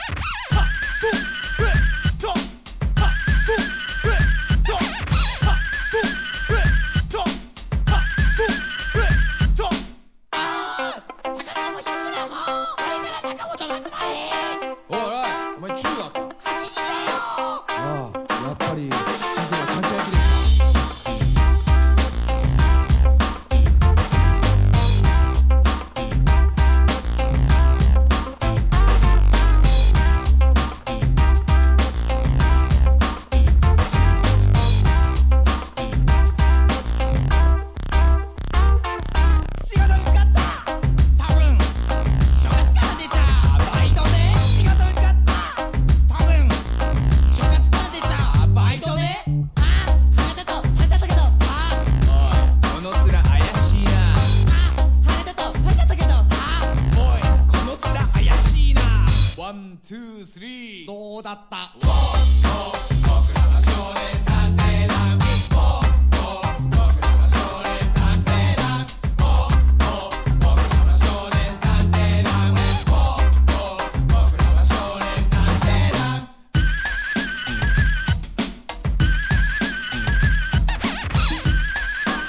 HIP HOP/R&B
ブレイクビーツ盤